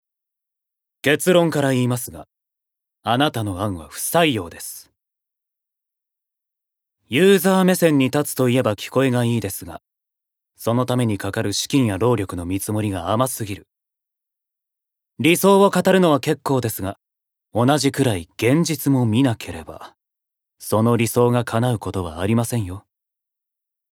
Voice Sample
ボイスサンプル
セリフ５